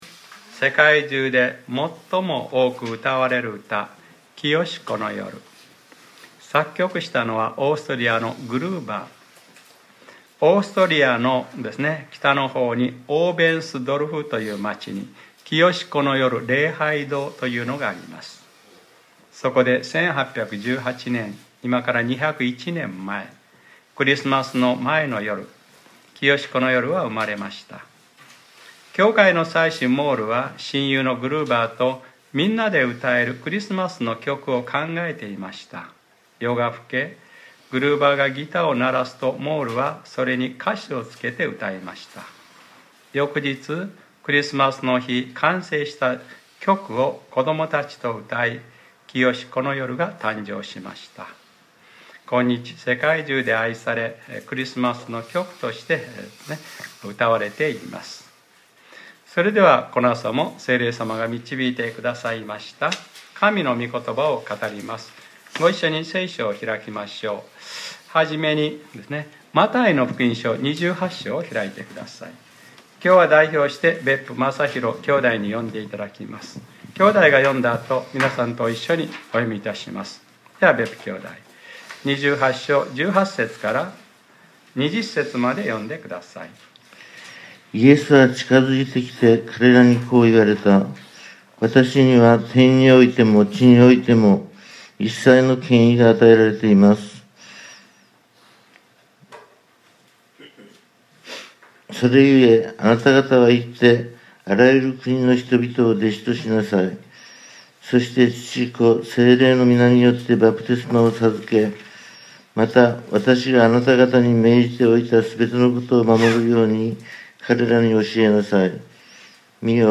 2019年12月08日（日）礼拝説教『７つの本質：この１年をふり返って』